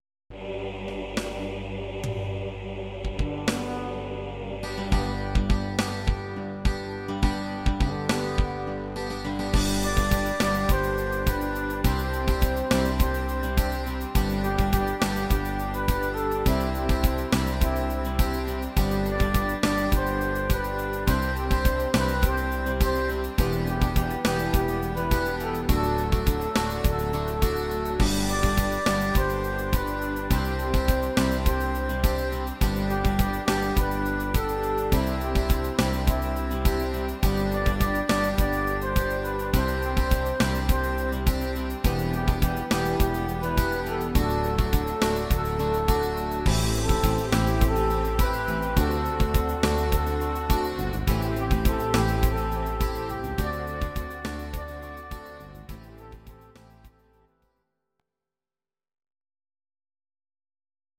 Audio Recordings based on Midi-files
Ital/French/Span, 1980s